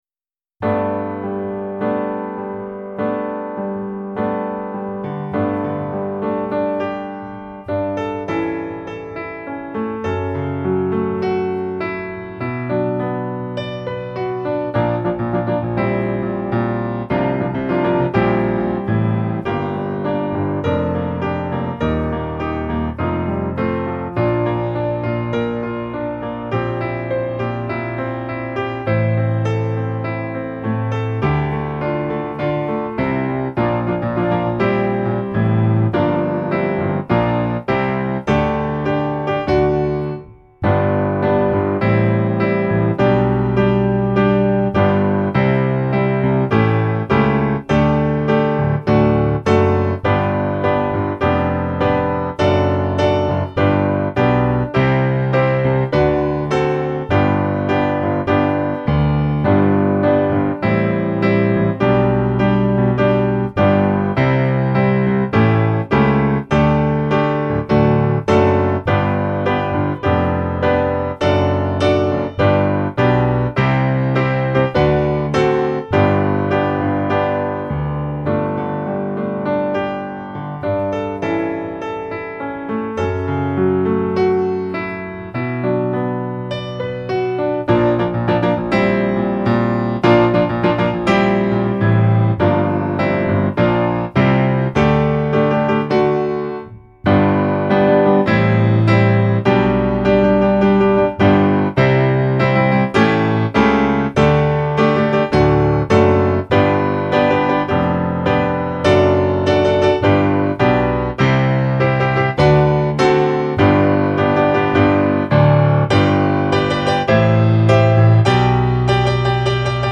podkład bez linii melodycznej